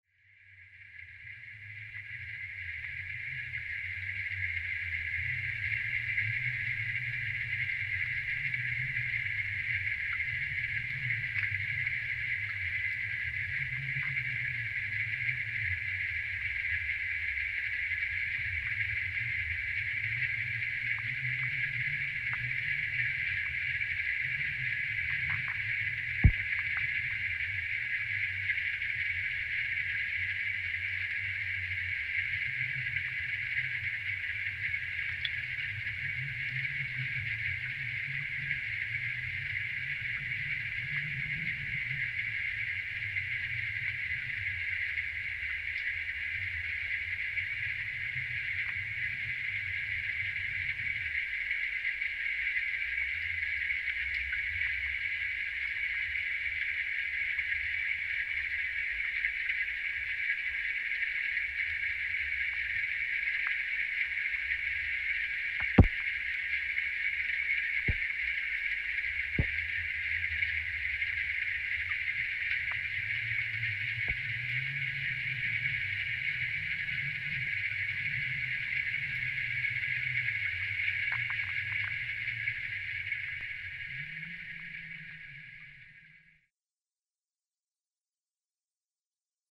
a friend once commented how many of my recordings are from a perspective up close. for me it is this perspective that I return to and find is important, an audible view of a situation – in this case a continuous event – that is not readily audible, or at least is greatly enhanced through amplification from the contact mic surface. for this release movement of water in two forms become the basis of exploration. one active one passive. each revealing some inherent noise, compression, hum and tension of existence.
Field Recording Series by Gruenrekorder
The source sounds are streams of water, rain, crackles that seem stones moved by the force of the water, wind blowing and birds.